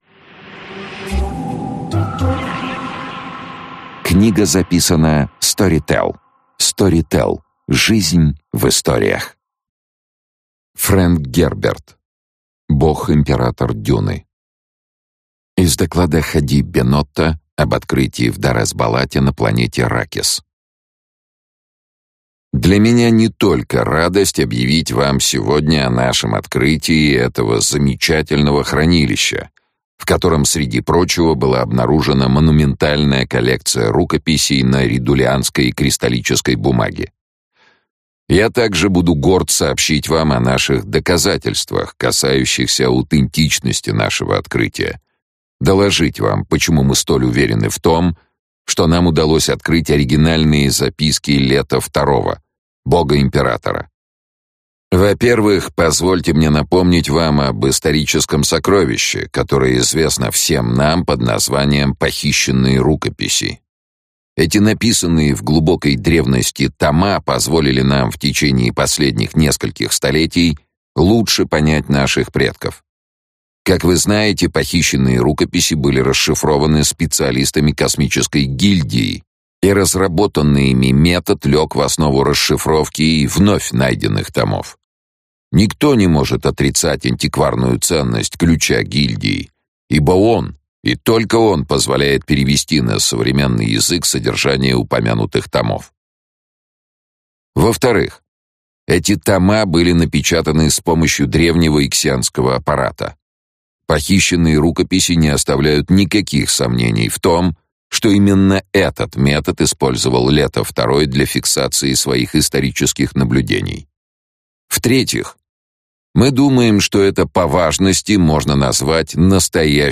Аудиокнига Бог-Император Дюны | Библиотека аудиокниг